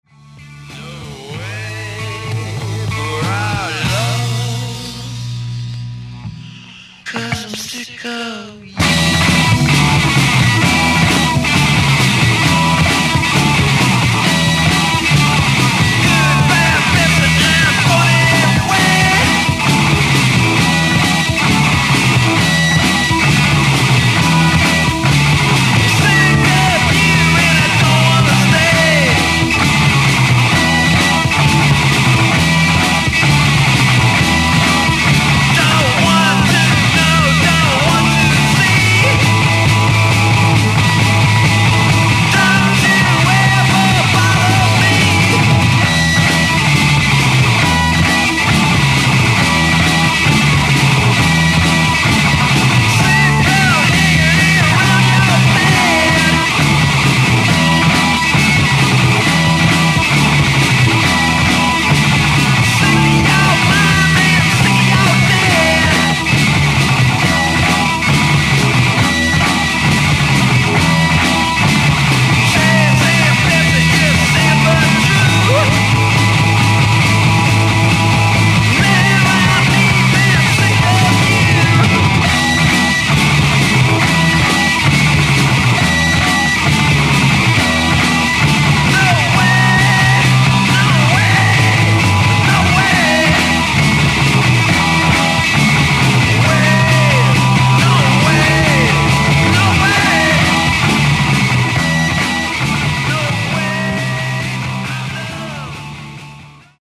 Le pattern rythmique est bien reconnaissable.
Même tonalité, tout !